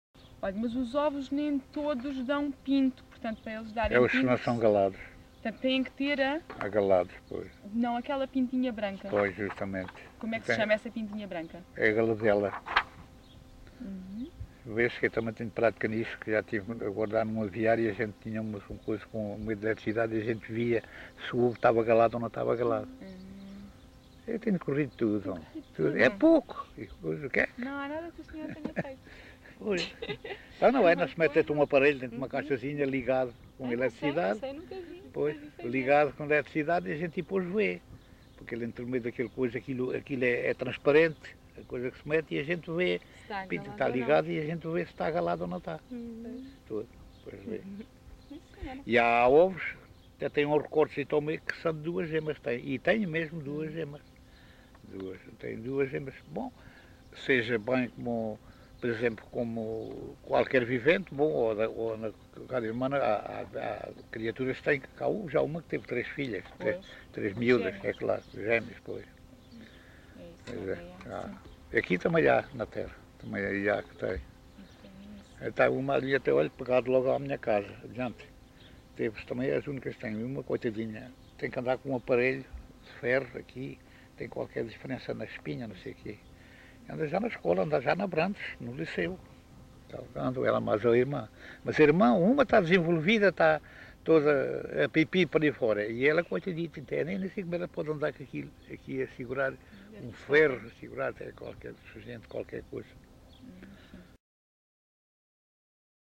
LocalidadeMontalvo (Constância, Santarém)